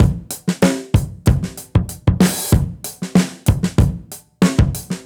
Index of /musicradar/dusty-funk-samples/Beats/95bpm